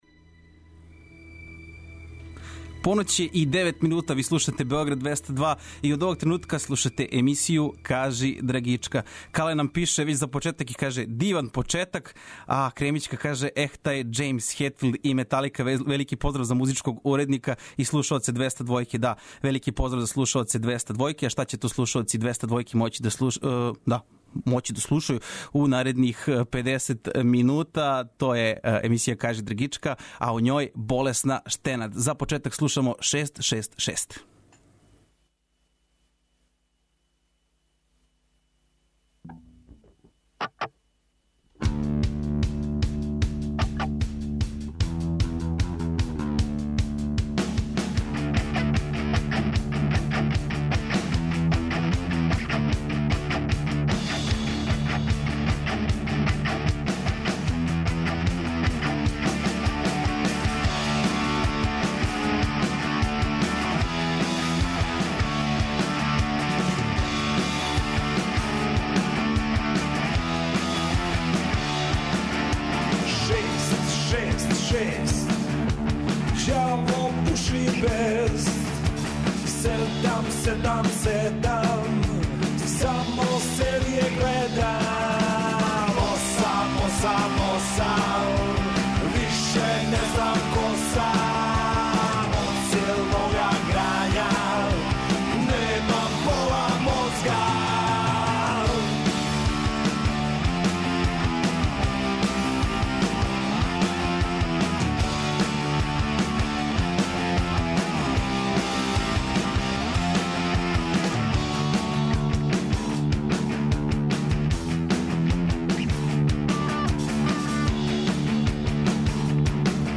Вечерас нам у госте долази група Болесна штенад! Причамо о предстојећим свиркама, од којих је једна и на Бир фесту, преслушавамо нове и старе песме, и музику коју 'Штенад' воле и која је утицала на њих.